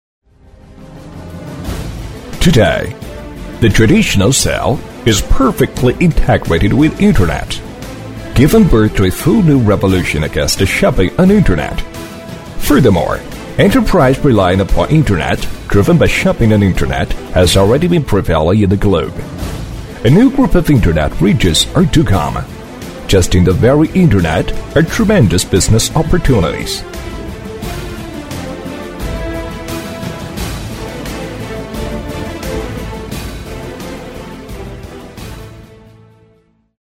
美式英语配音